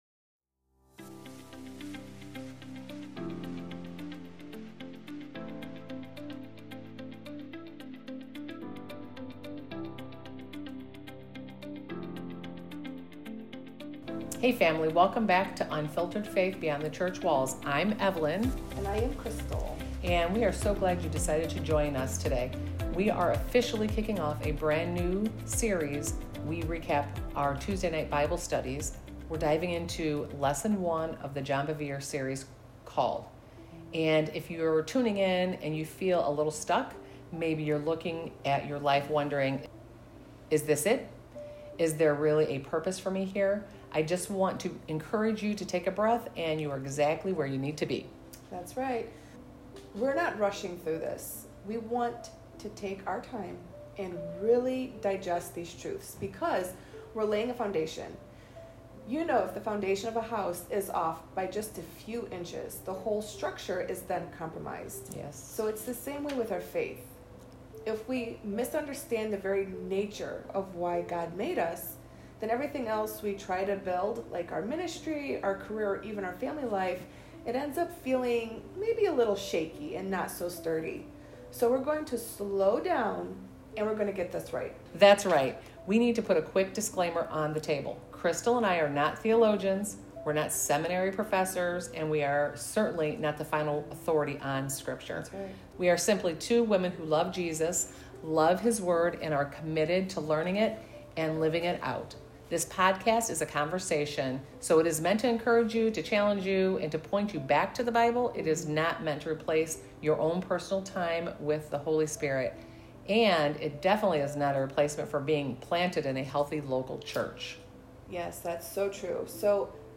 📖 Reminder: We’re not Bible scholars or pastors—just women who love God’s Word.